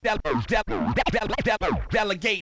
SCRATCHING  (1).wav